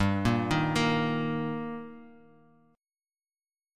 Gm#5 chord